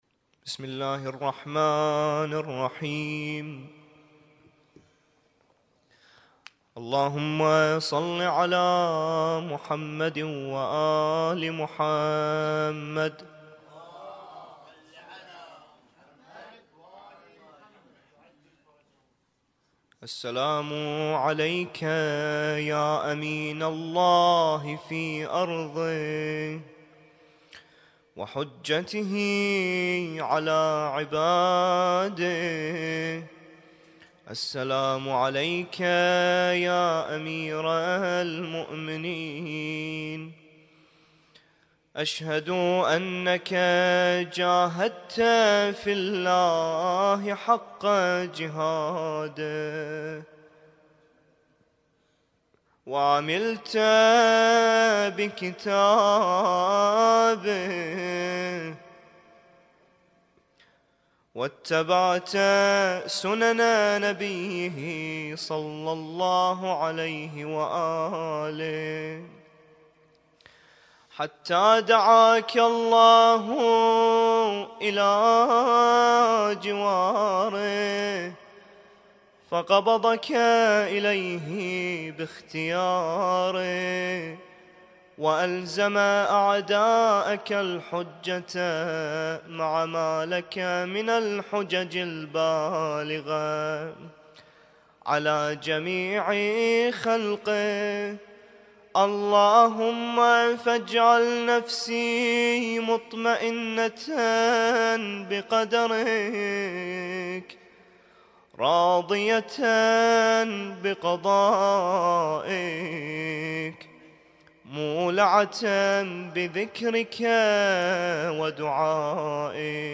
ليلة (12) من شهر رجب 1447هـــ